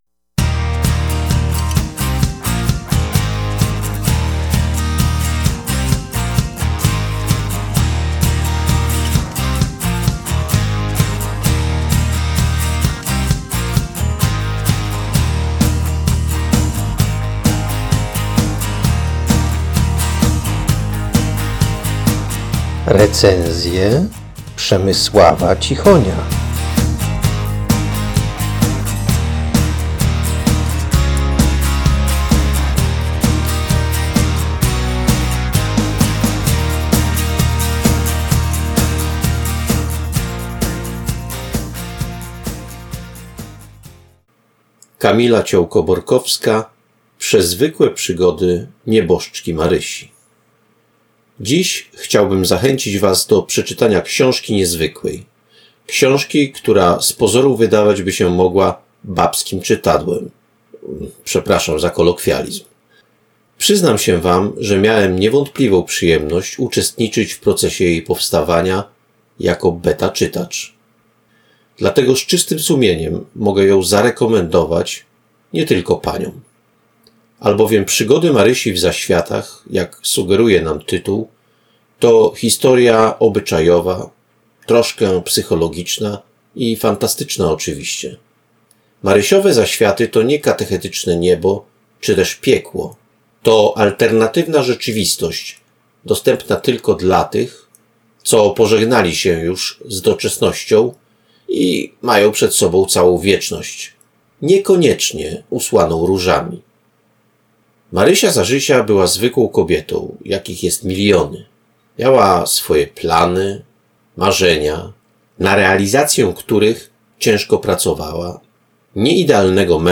Czyta